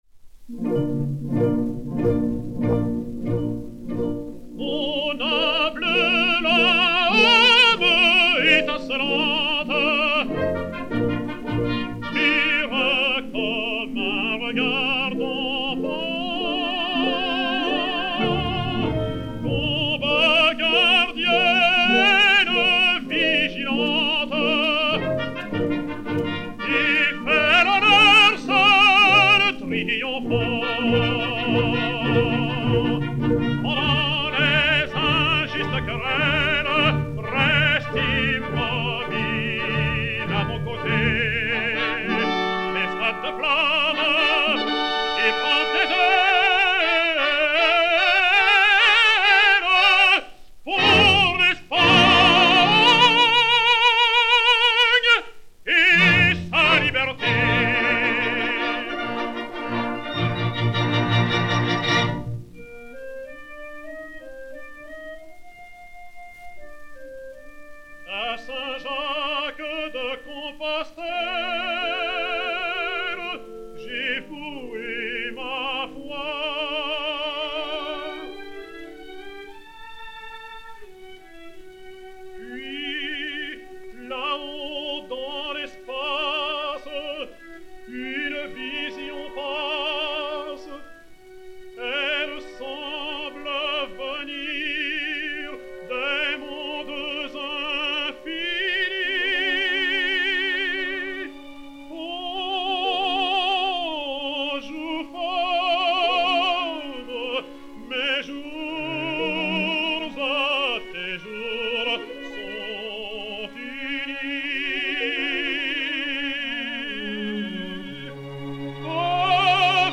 Georges Thill (Rodrigue) et Orchestre dir. Eugène Bigot
LX 1661-2, enr. à Paris le 02 février 1933